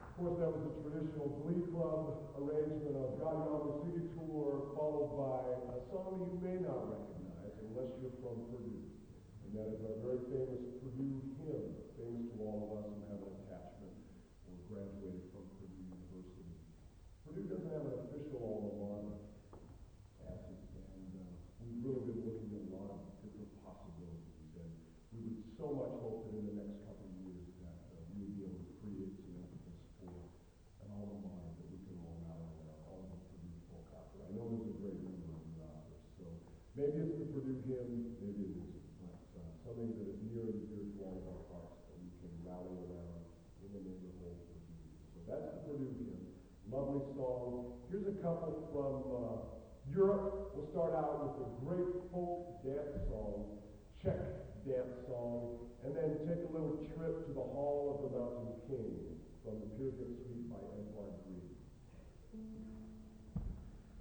Collection: South Bend 1990